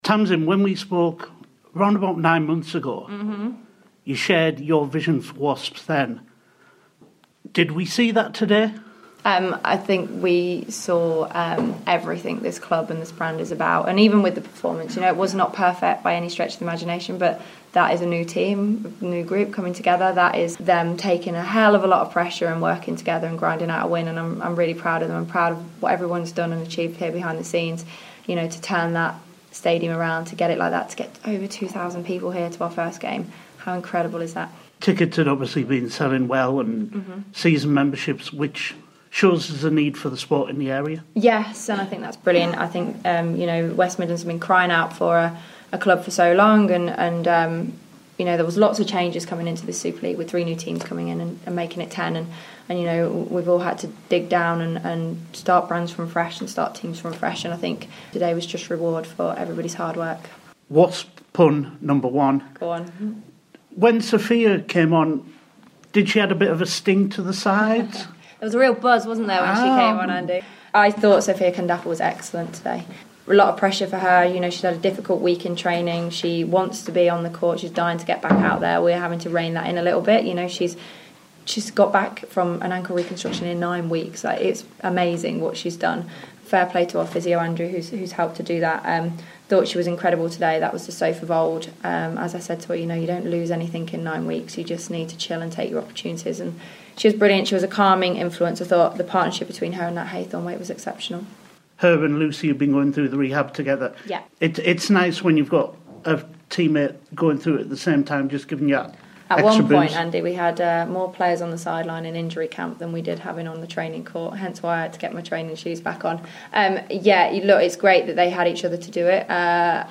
Here's Wasps Director Of Netball Tamsin Greenway direct from the ""media coffee lounge"" as Wasp's Netball first home game resulted in a 51-47 victory over Mavs